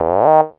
45 SYNTH 5-L.wav